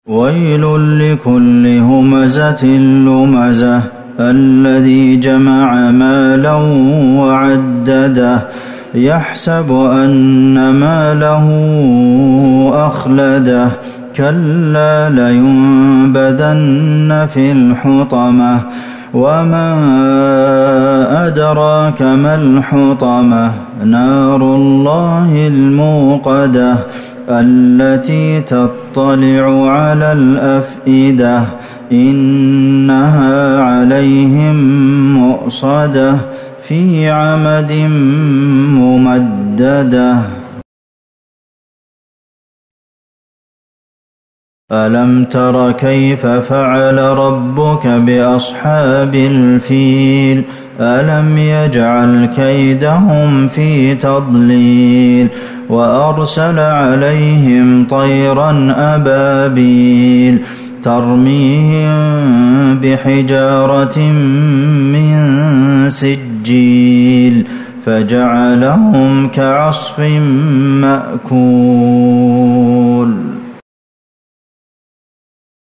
صلاة المغرب ١٤٤١/١/١هـ سورتي الهمزة والفيل | Maghrib prayer Surah Al-Humazah and Al-Fil > 1441 🕌 > الفروض - تلاوات الحرمين